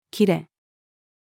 切れ-female.mp3